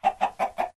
chicken2.ogg